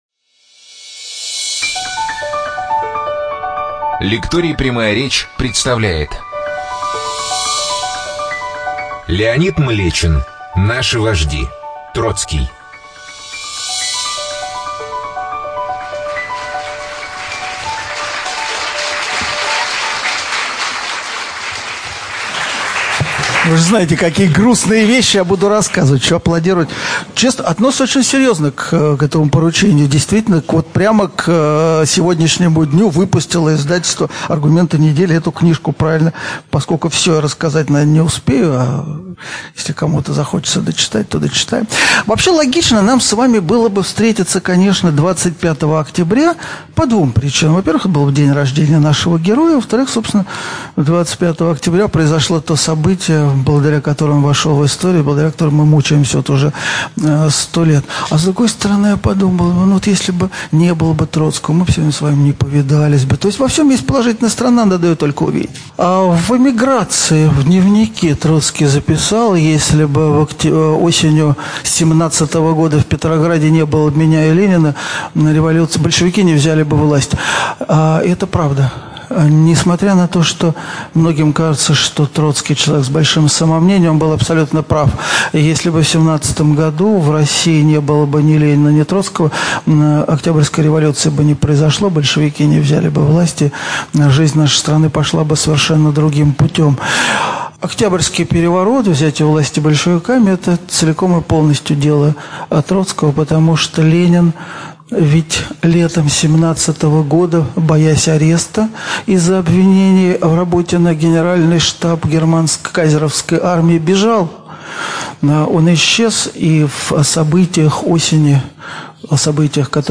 ЧитаетАвтор
Студия звукозаписиЛекторий "Прямая речь"
Млечин Л - Наши вожди. Троцкий. Лекция (Автор)(preview).mp3